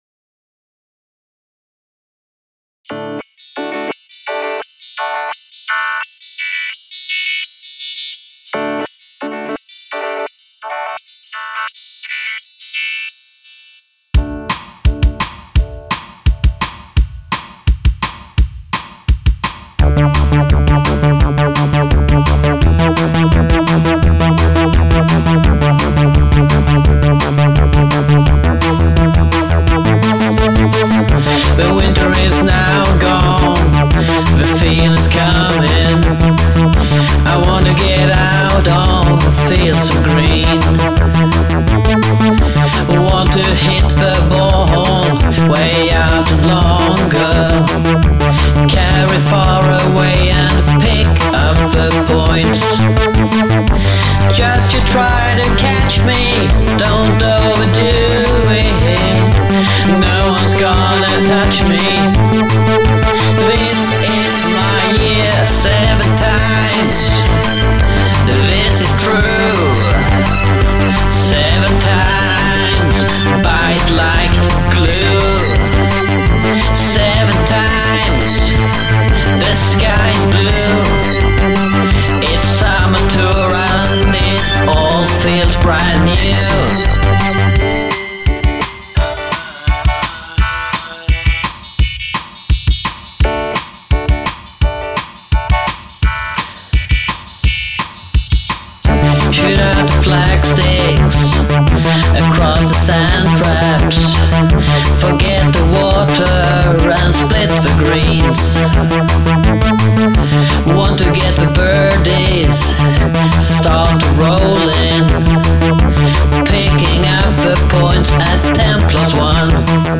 förra årets golflåt: